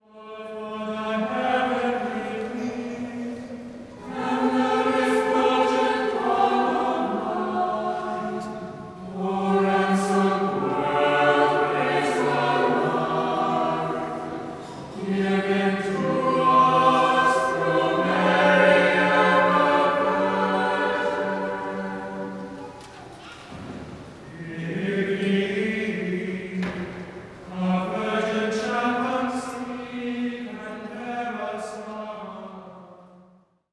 Imagine walking up into a choir loft and sounding like these young ladies without rehearsal.
* PDF Download • “Maria Walks” (for three voices) —Another excellent translation of Maria Durch Ein’ Dornwald Ging is #772 in The Saint Jean de Brébeuf Hymnal.